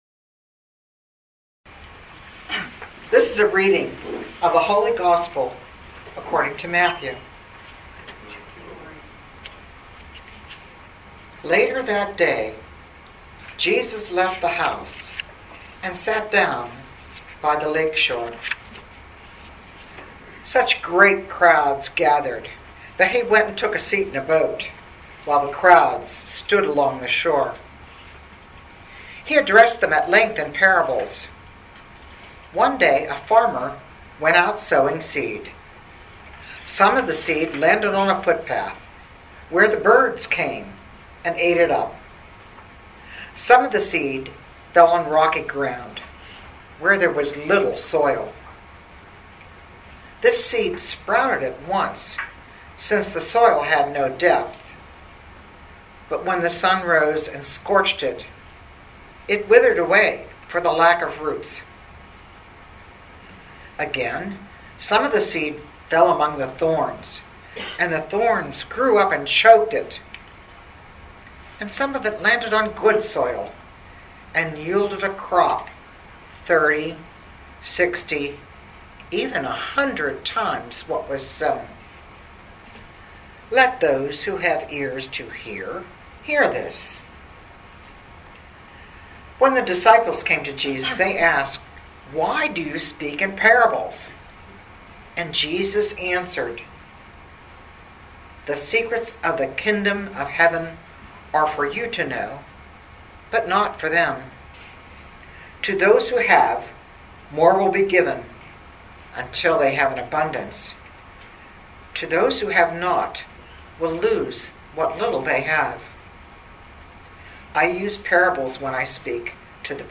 Living Beatitudes Community Homilies: The Sower